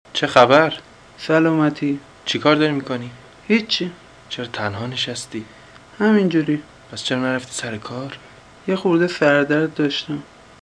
4.  Dictation: Listen to this